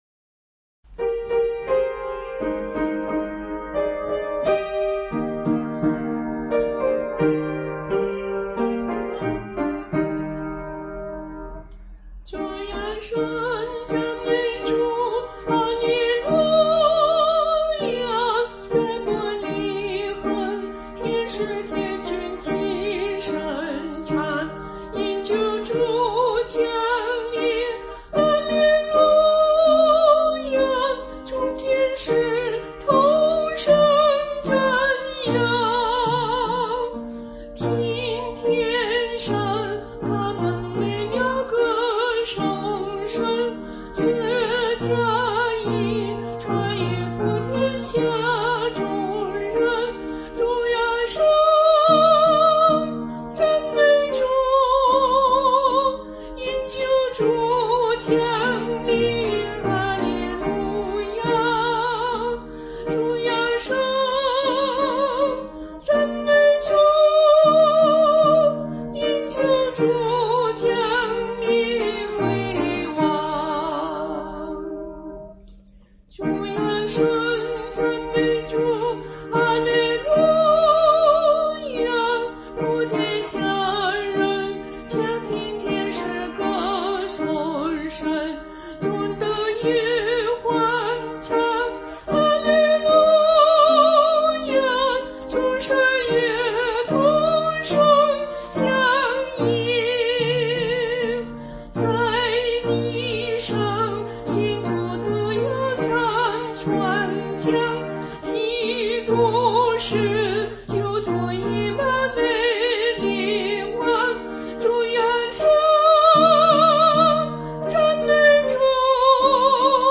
伴奏
它的和声并不复杂，只用了主和弦，属和弦和下属和弦，曲调的音域和适合大众献唱。